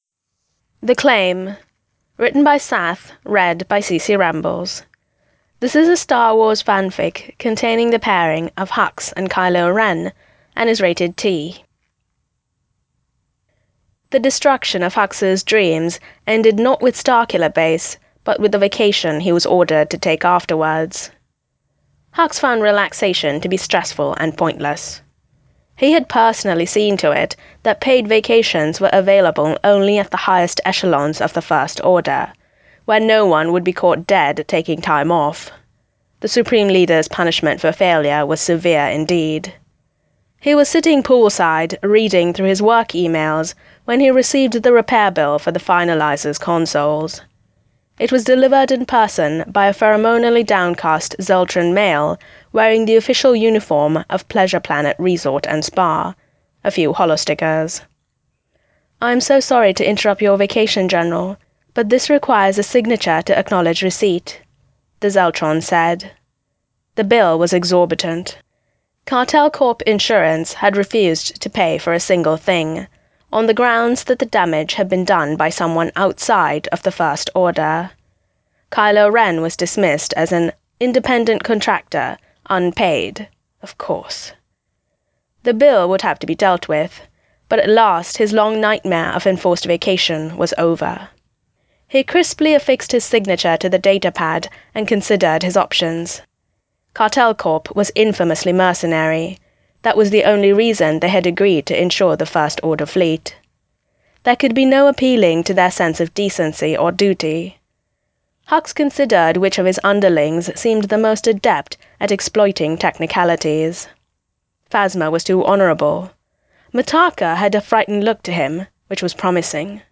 [Podfic] The Claim